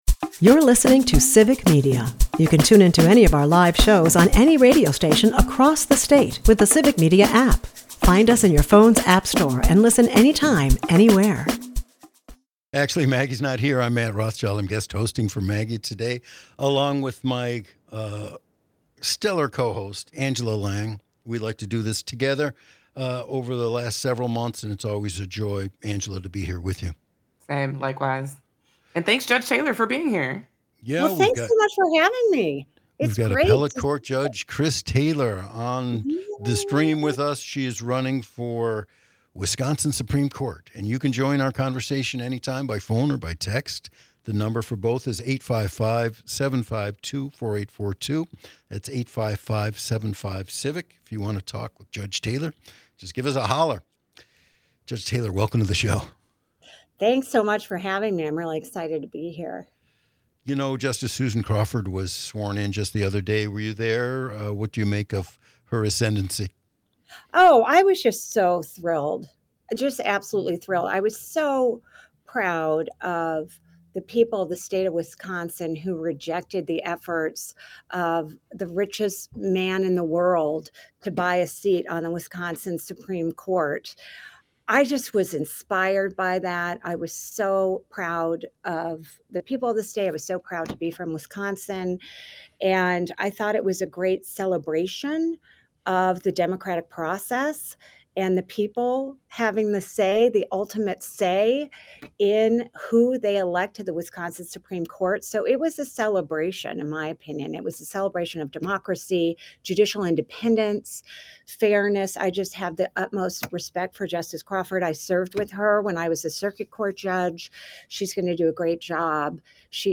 Callers stress the need for unity and a leader who celebrates Wisconsin's diverse communities.